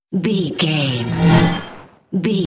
Sci fi appear whoosh debris
Sound Effects
futuristic
high tech
whoosh